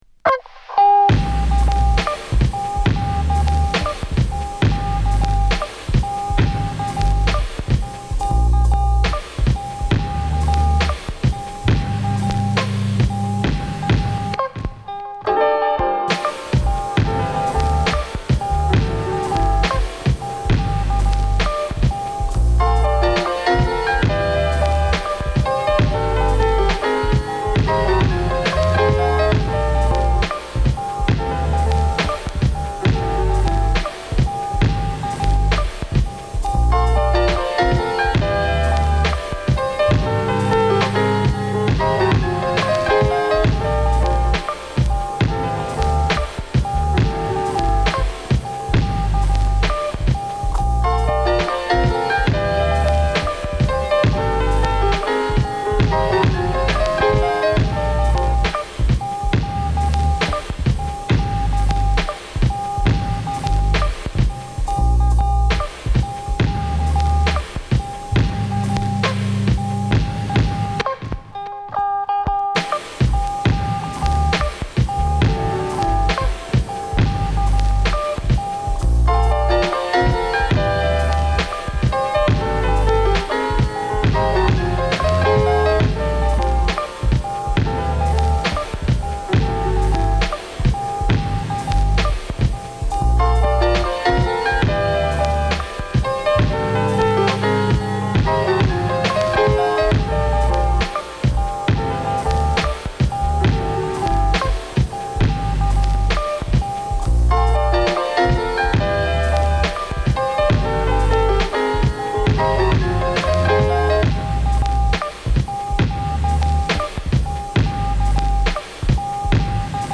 インストサウンドを基調としながらグッとくる切ない瞬間や温かいボトムラインのモダンなジャジービーツに魅力を感じます。